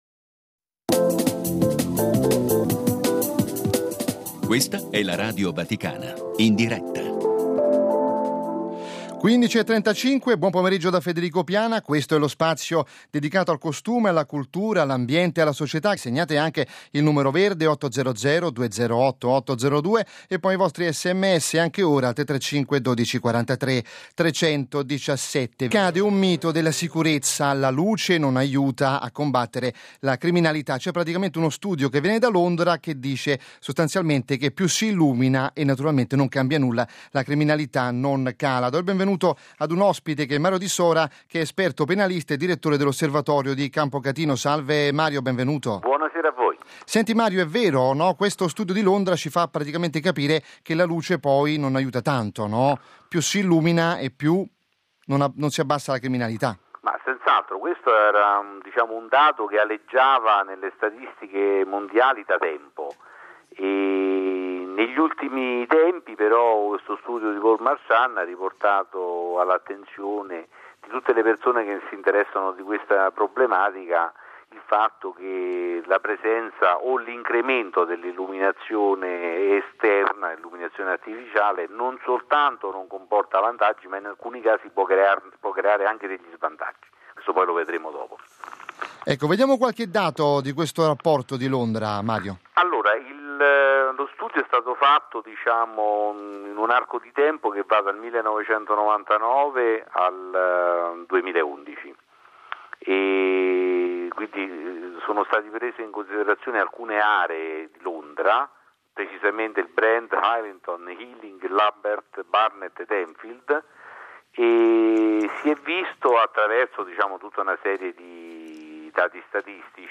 Andato in onda il 28 gennaio all’interno del programma “Cultura e Società”, l’intervista affronta un tema sempre discusso quando si parla di illuminazione. L’idea comune è quella di fare l’associazione “più luce, meno criminalità”.